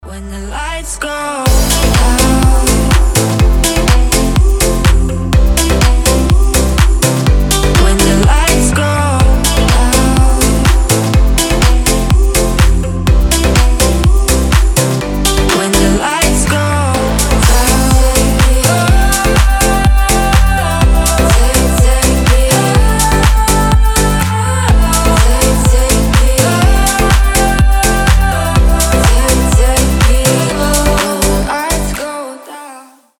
• Качество: 320, Stereo
женский вокал
deep house
мелодичные